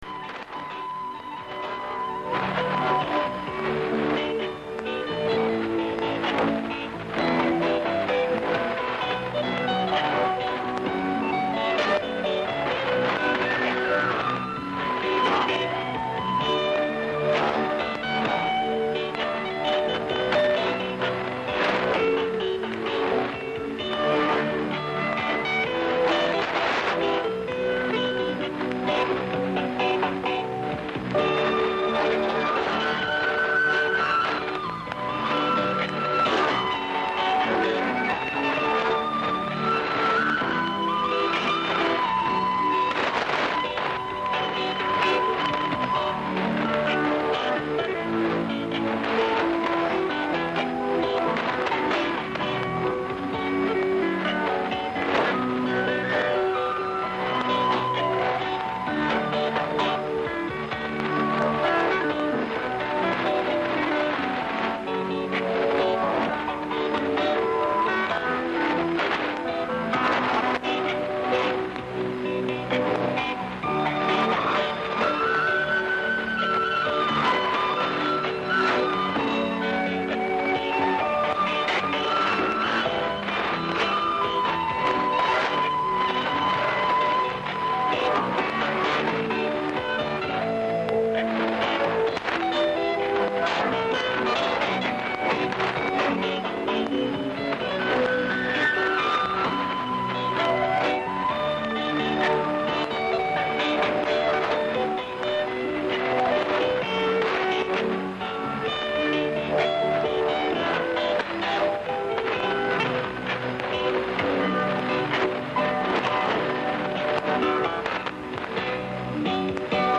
Voici quelques extraits d'émissions, pas toujours en français en fonction de l'heure d'écoute, évidement ce n'est pas de la FM ( haute fidélité ) ou du       satellite mais c'est du direct toutes les radios furent écoutées la même soirée en 3H00 de temps